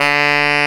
Index of /90_sSampleCDs/Roland L-CD702/VOL-2/SAX_Tenor mf&ff/SAX_Tenor ff
SAX TENORF03.wav